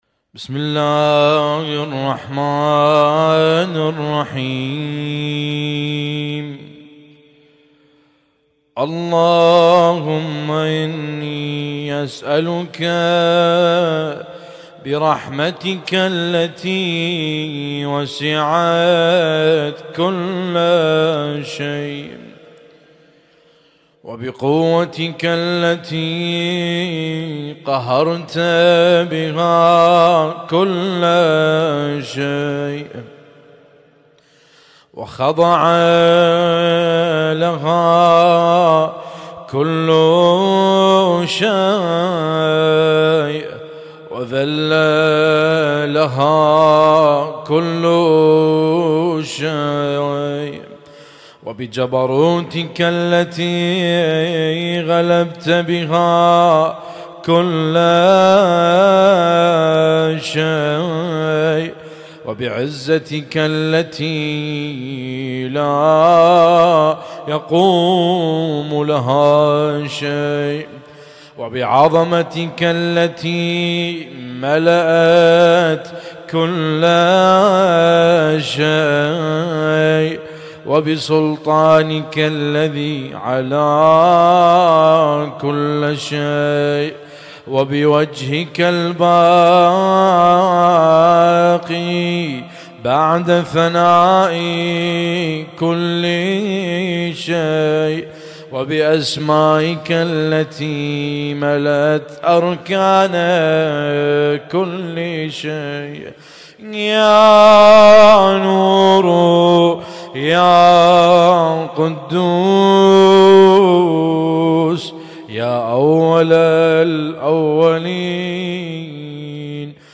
اسم التصنيف: المـكتبة الصــوتيه >> الادعية >> دعاء كميل